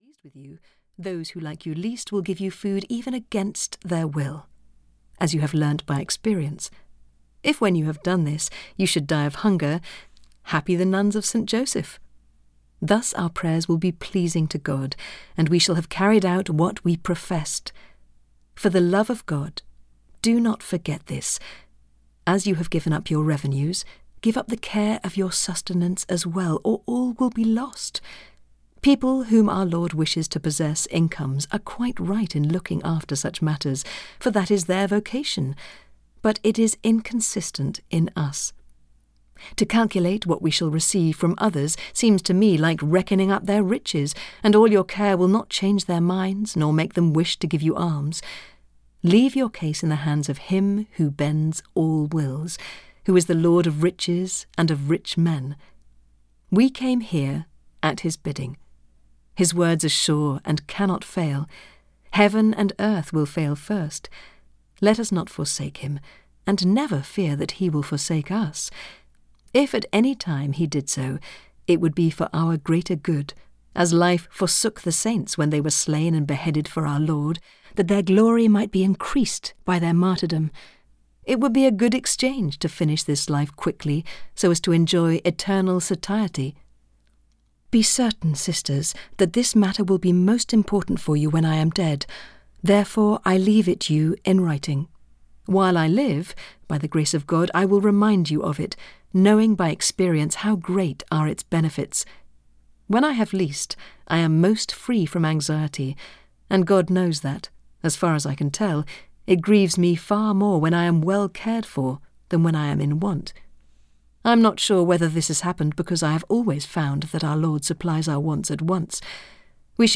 The Way of Perfection (EN) audiokniha
Ukázka z knihy